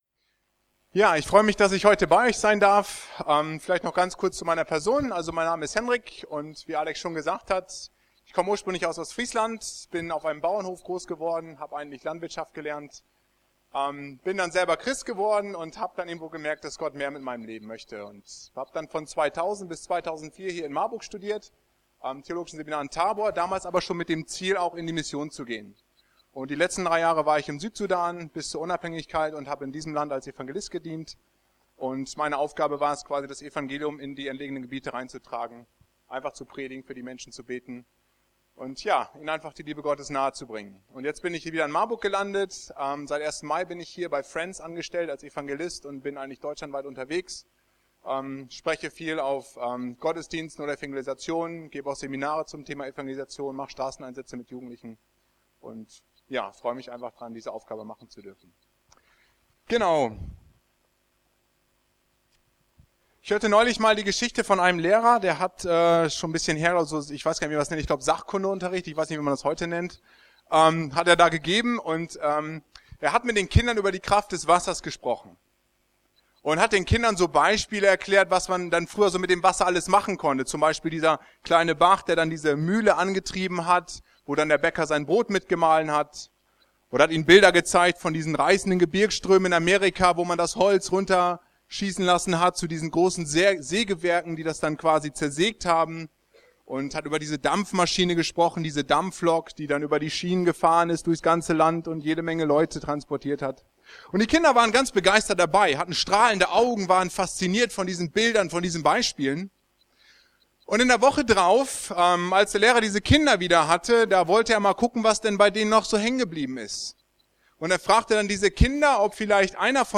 Mehr als nur Brotkrümel | Marburger Predigten